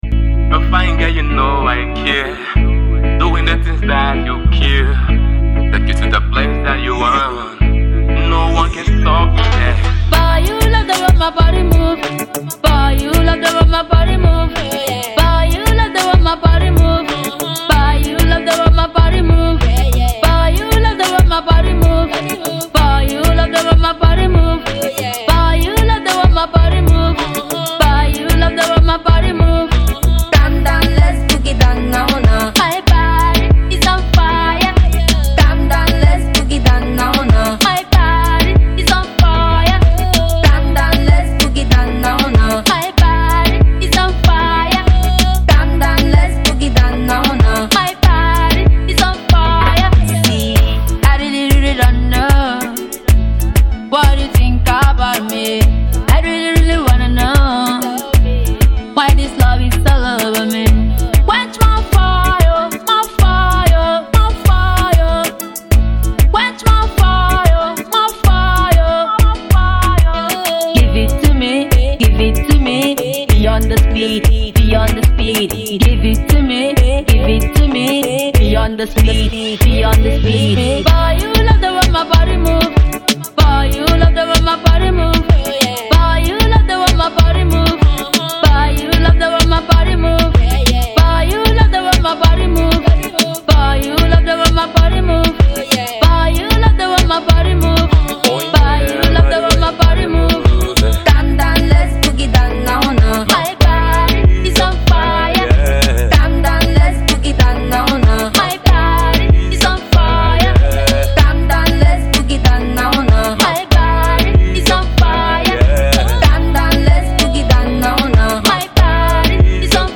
female love/gospel singer/songwriter